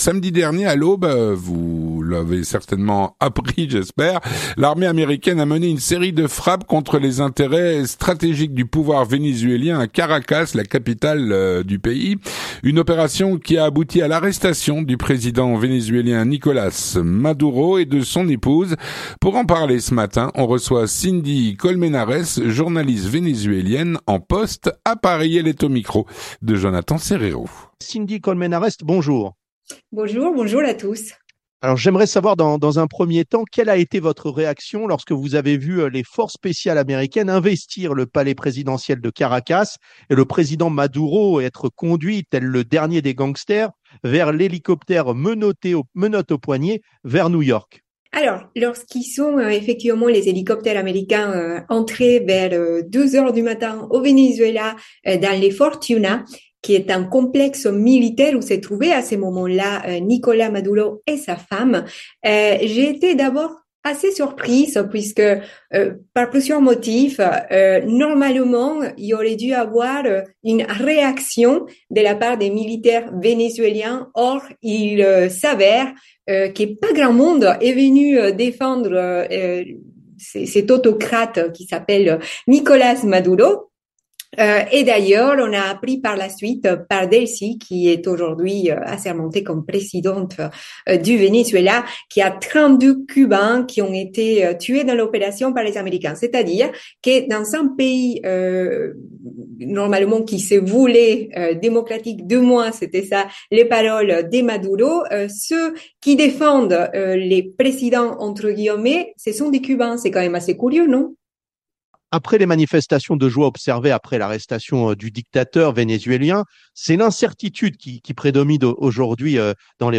L'entretien du 18H - L'arrestation du Président Vénézuélien Nicolàs Maduro.